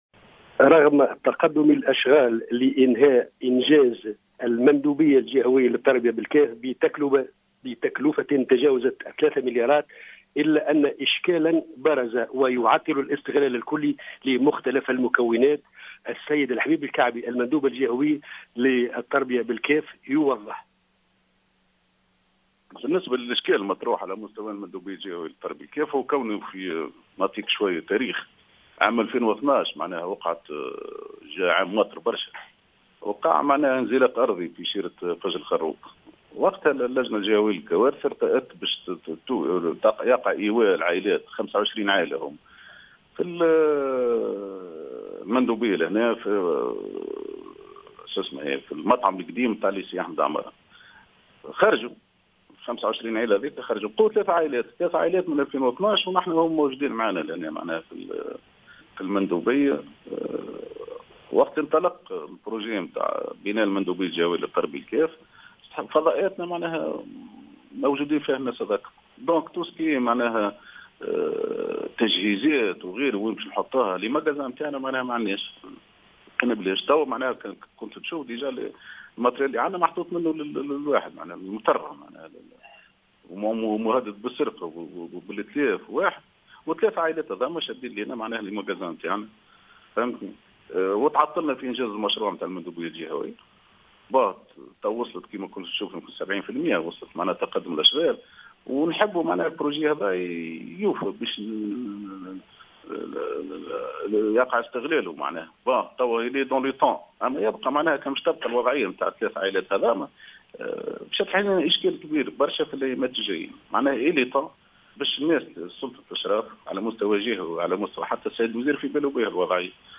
وأوضح الحبيب العكبي المندوب الجهوي للتربية بالجهة في تصريح لمراسل الجوهرة اف ام، أن أطوار الإشكال تعود إلى سنة 2012، حيث قررت اللجنة الجهوية لمجابهة الكوارث إيواء 25 عائلة في المندوبية بسبب انزلاق أرضي نتيجة تهاطل كميات كبيرة من الأمطار.